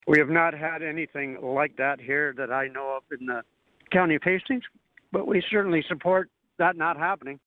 Hastings County Warden Rick Phillips spoke with Quinte News.